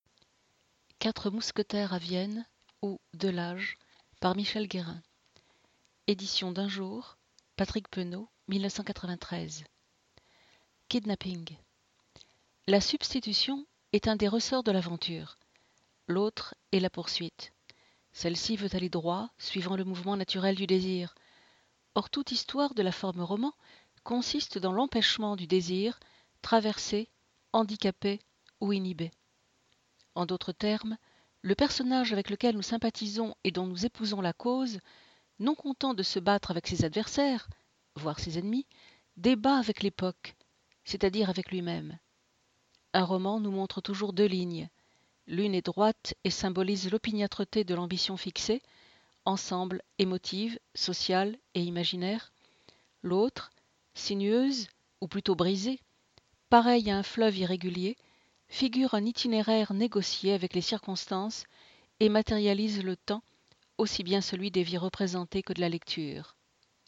Extrait sonore : chapitre Kidnapping. (Essai sur la trilogie d´Alexandre Dumas)